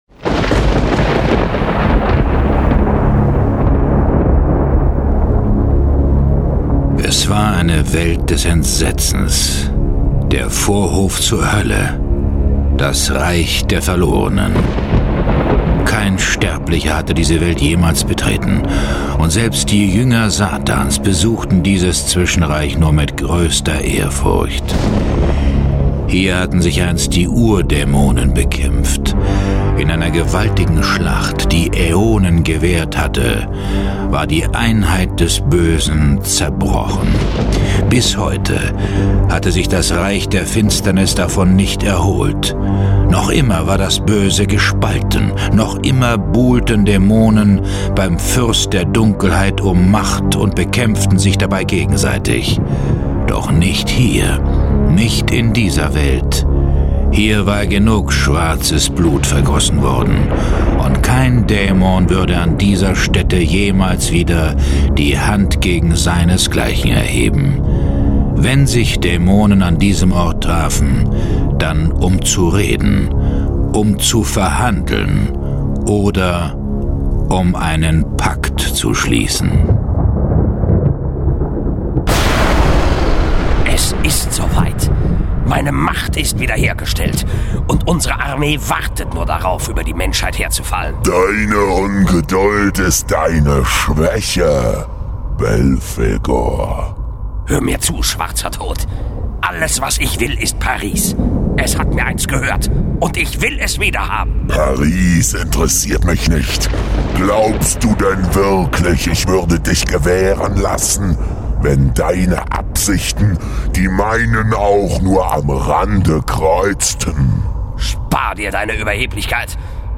John Sinclair - Folge 12 Der Hexer von Paris. Hörspiel.